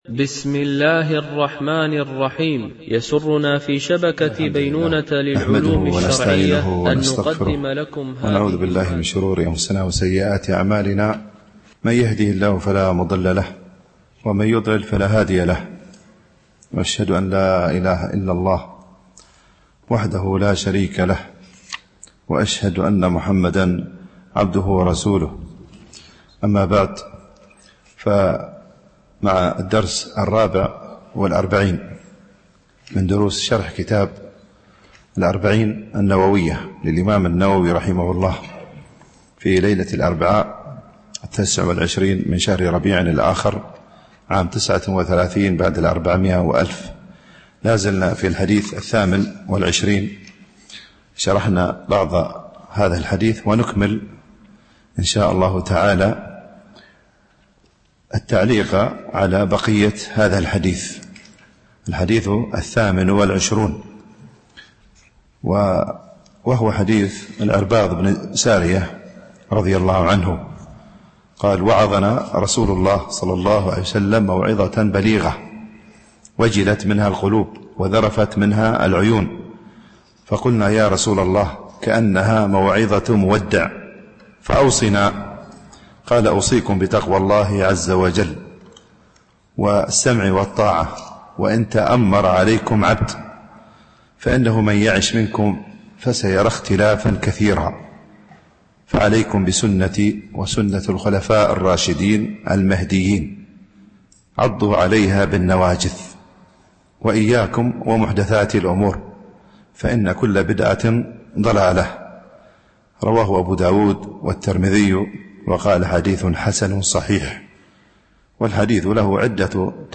شرح الأربعين النووية ـ الدرس 44 (الحديث 28)